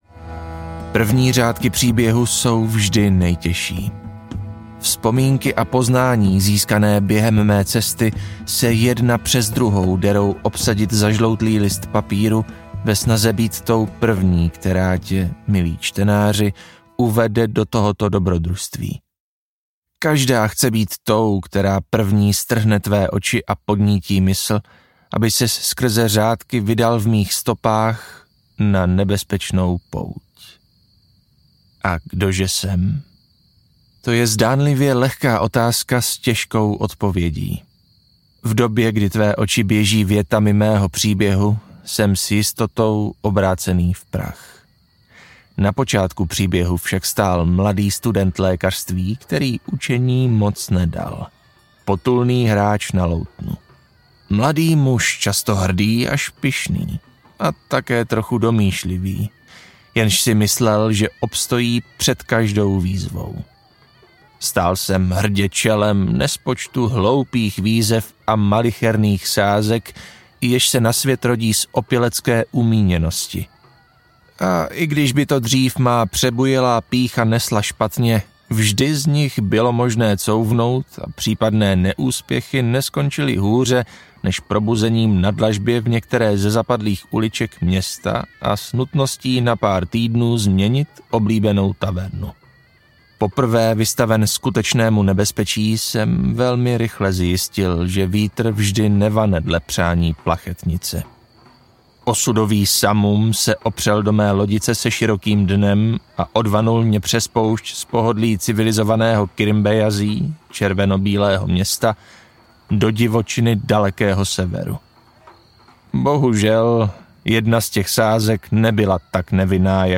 Příběh jednoho kmene audiokniha
Ukázka z knihy
• InterpretLibor Böhm